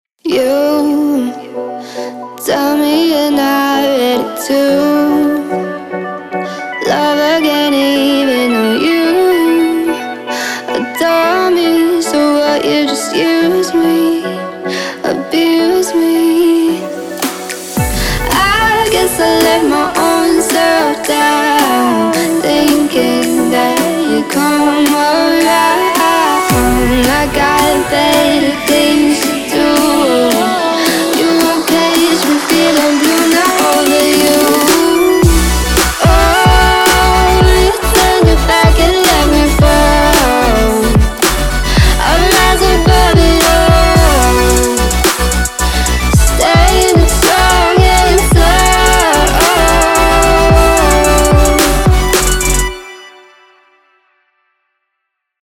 two professional vocalists, one male and one female